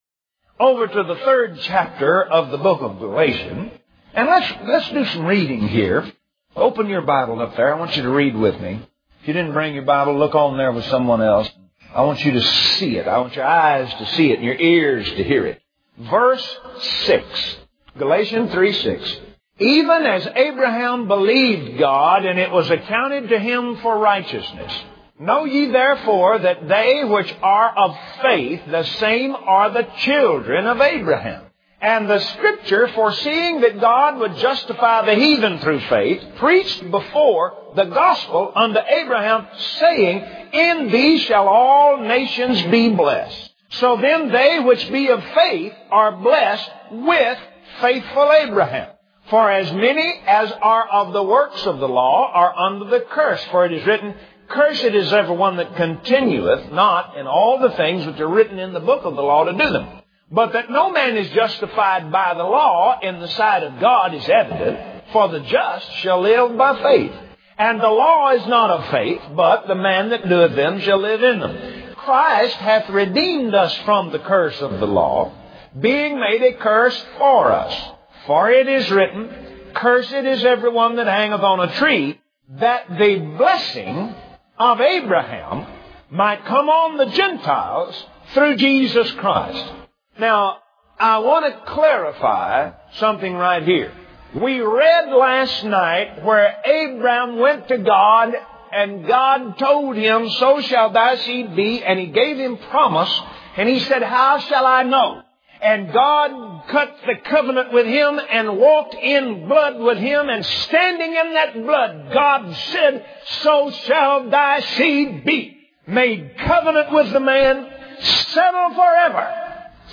Index of /Audio/Sermons/Guest_Speakers/Kenneth_Copeland/Power_To_Prosper/